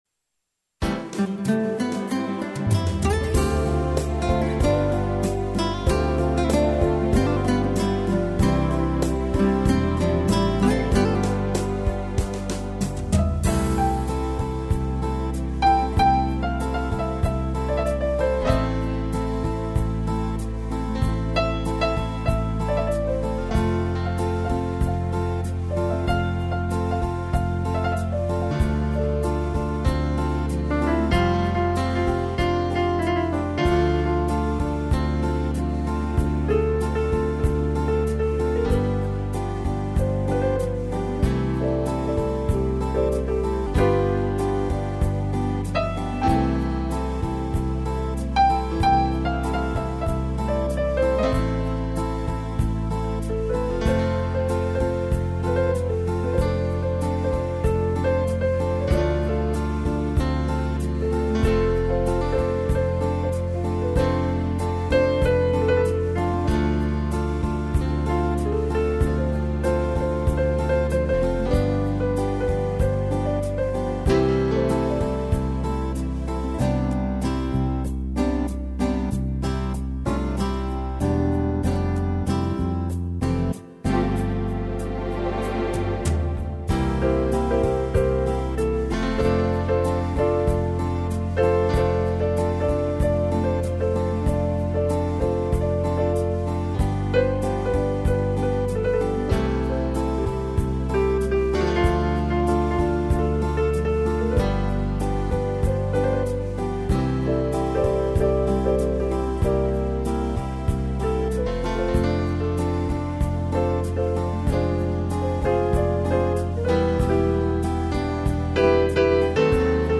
arranjo e interpretação teclado